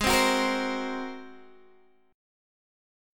Listen to G#9 strummed